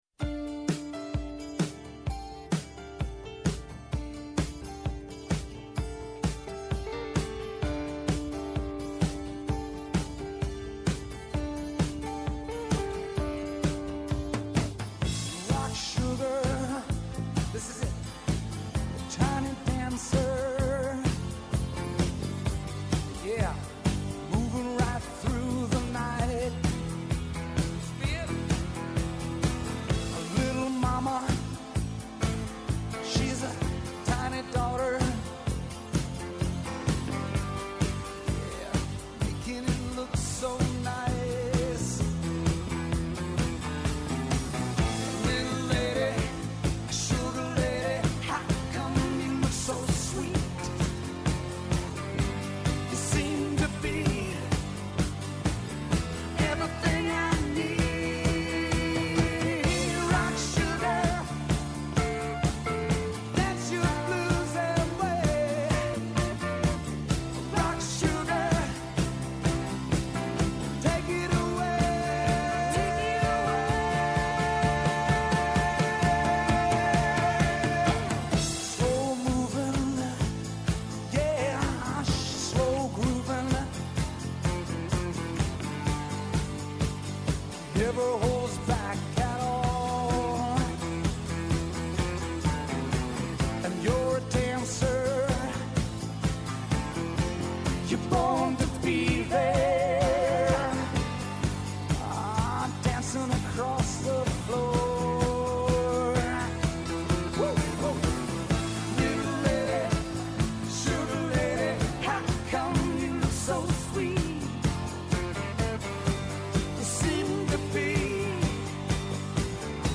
Καλεσμένος σήμερα ο Σωτήρης Αναγνωστόπουλος, Γενικός Γραμματέας Εμπορίου και Προστασίας Καταναλωτή